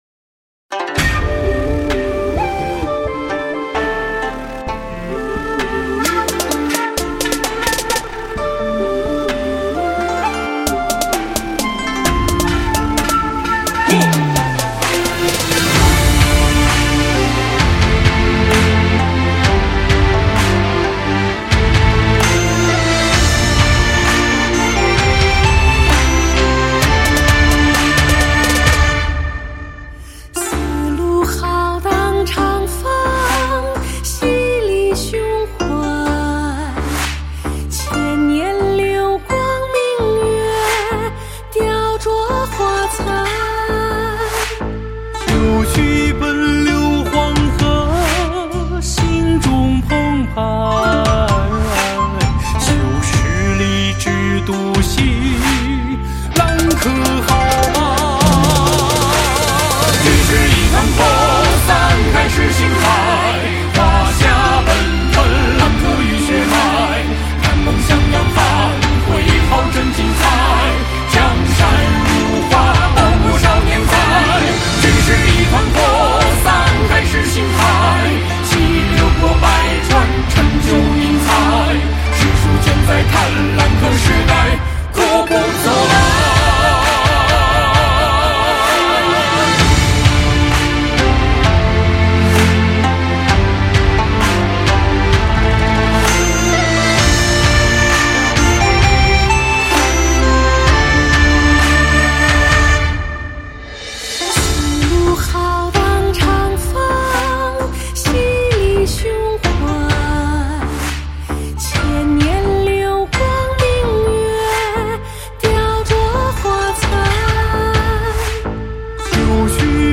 校歌
校歌伴奏